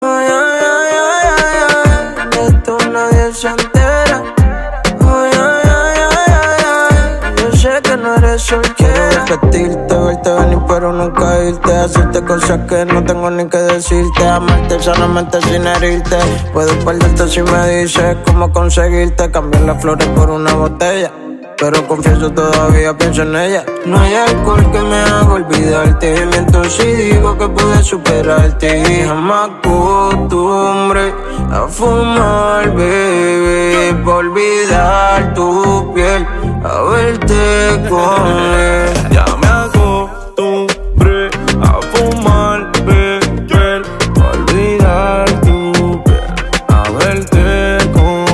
électronique